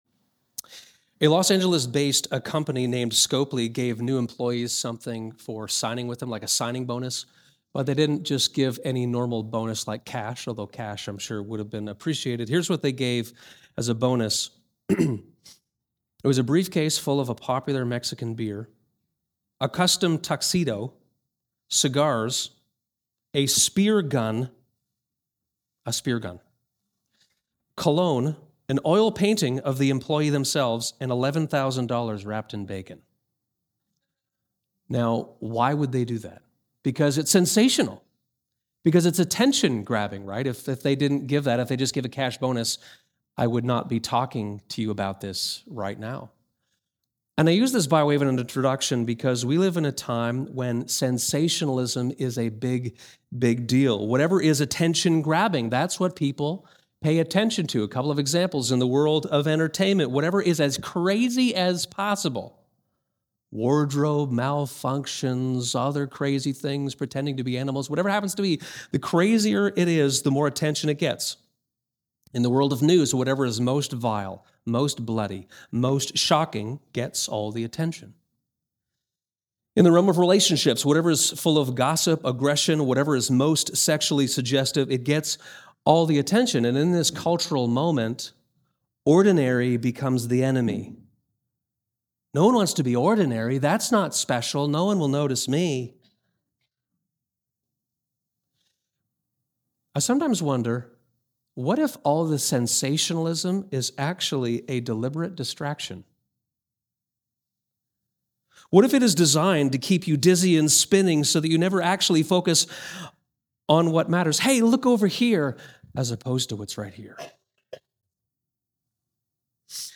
Sermons | Westminster